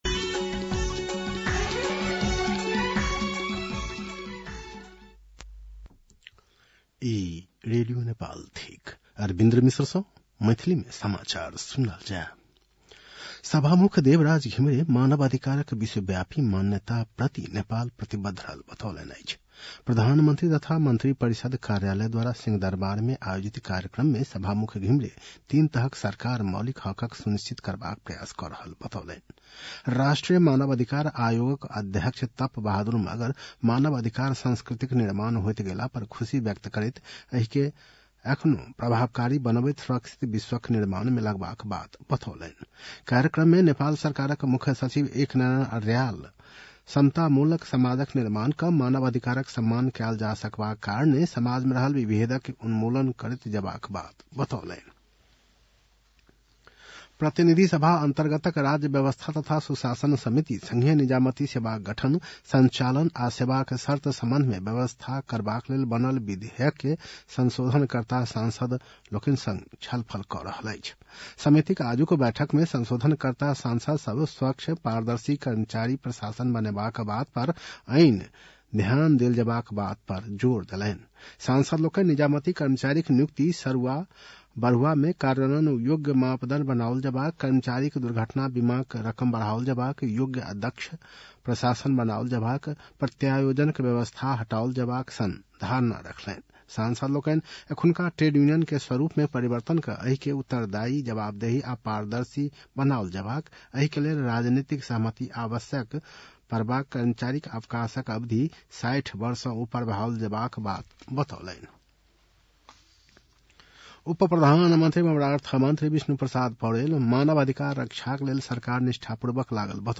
मैथिली भाषामा समाचार : २६ मंसिर , २०८१
Maithali-news-8-25.mp3